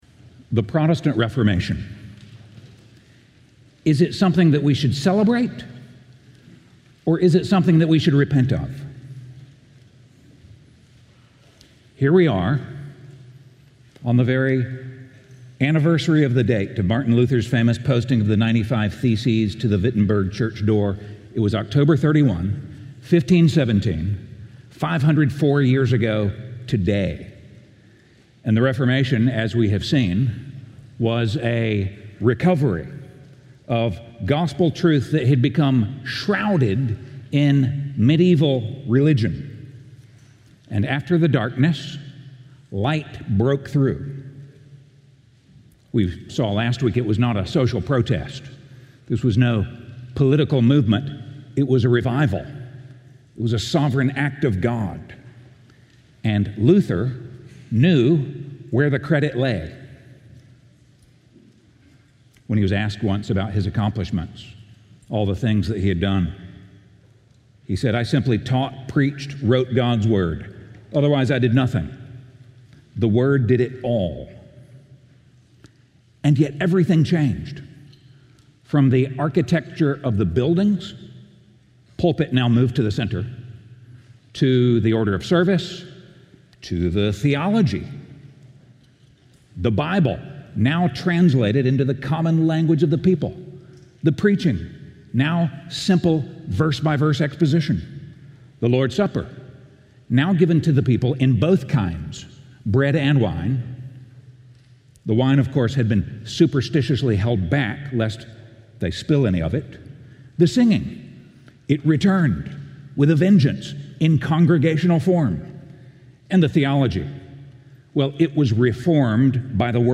Livestream Video & Sermon Audio